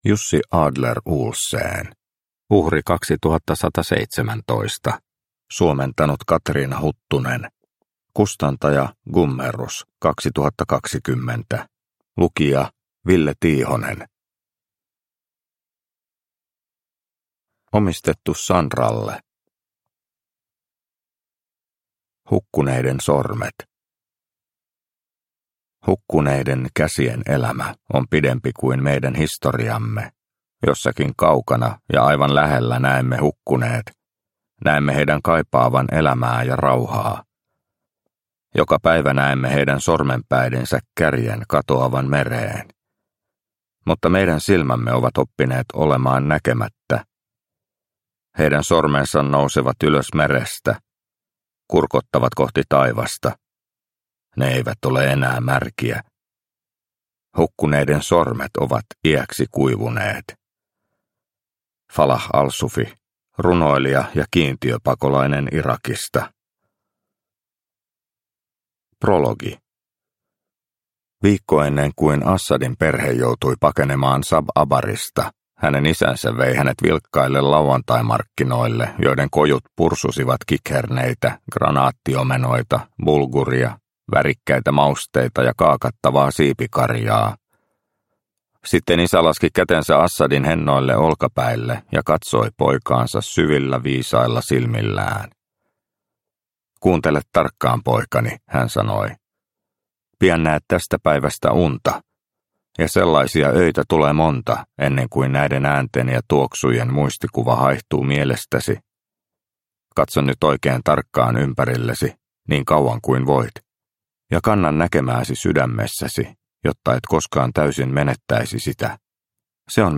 Uhri 2117 – Ljudbok – Laddas ner